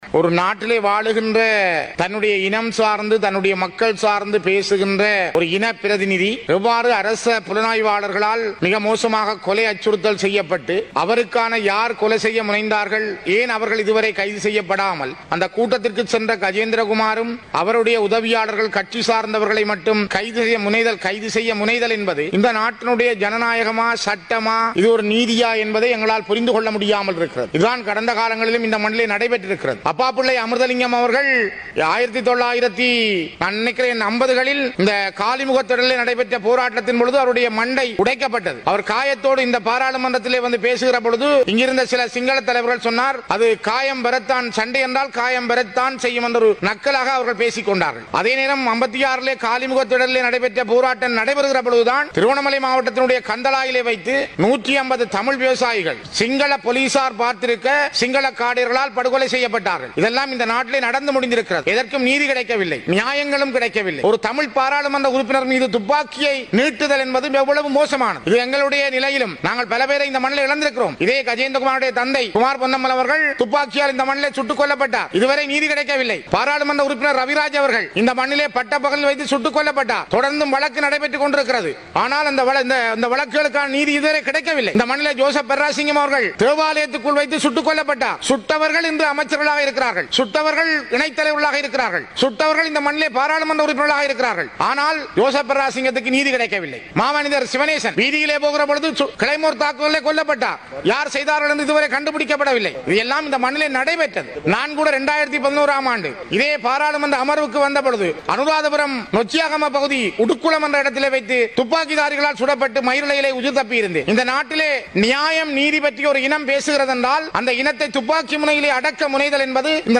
பாராளுமன்றத்தில் செவ்வாய்க்கிழமை நடைபெற்ற விமான கட்டளை சட்டங்கள் தொடர்பான விவாதத்தில் உரையாற்றும் போதே மேற்கண்டவாறு தெரிவித்த அவர் மேலும் தெரிவிக்கையில், “யாழ்.